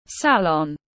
Thẩm mỹ viện tiếng anh gọi là salon, phiên âm tiếng anh đọc là /ˈsæl.ɒn/.
Salon /ˈsæl.ɒn/